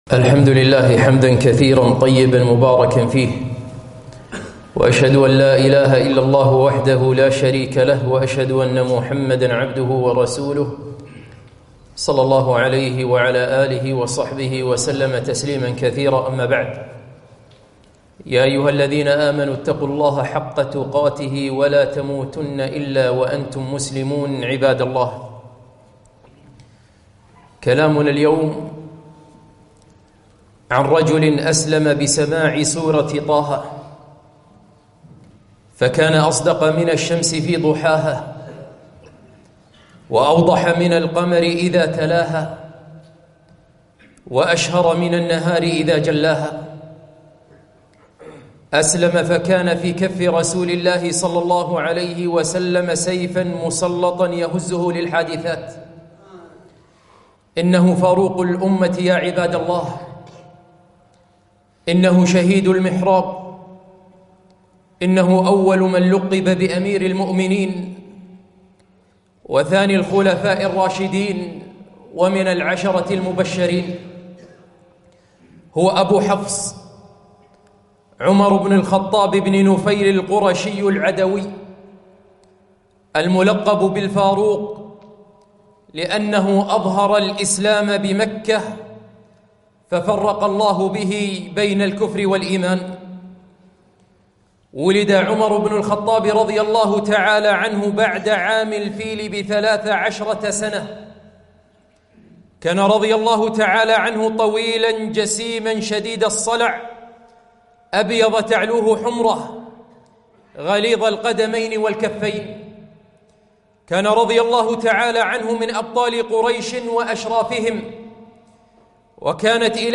خطبة - عمر بن الخطاب رضي الله عنه